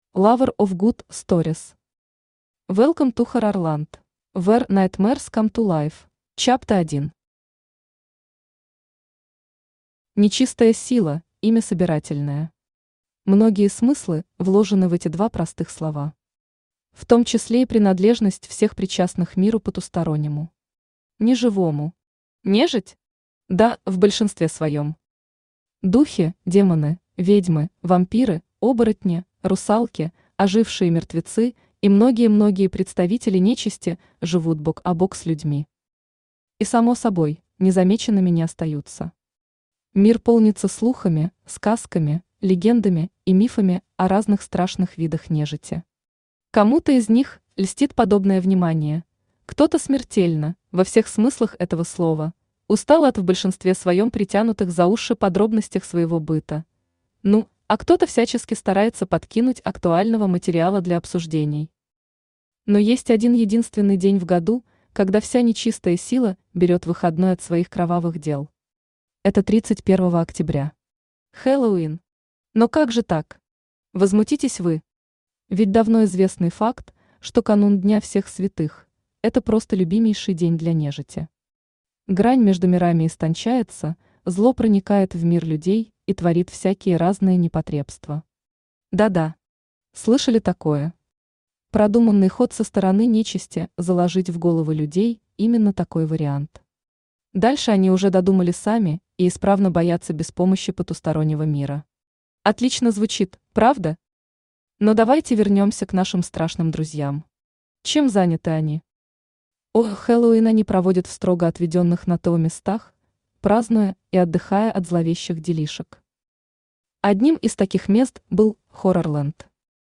Aудиокнига Welcome to Horrorland
Читает аудиокнигу Авточтец ЛитРес